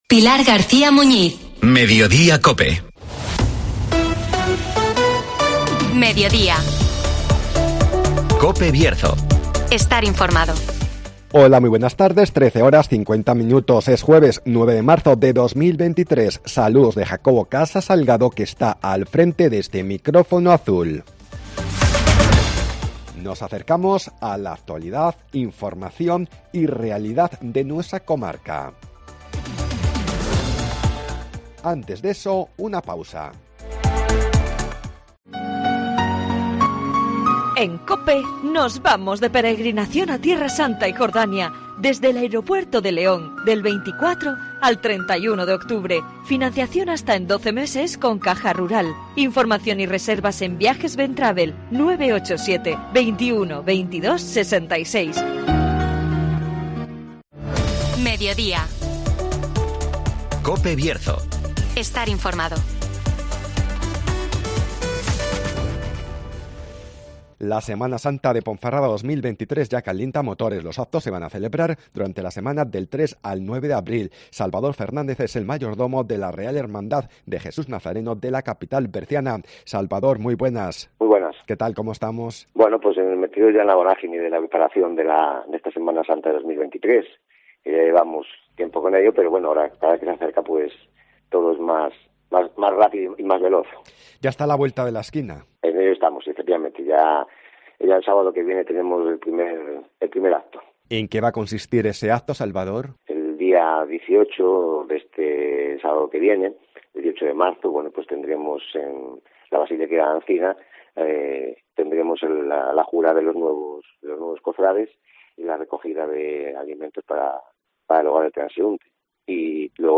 La Semana Santa de Ponferrada 2023 ya calienta motores (Entrevista